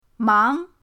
mang2.mp3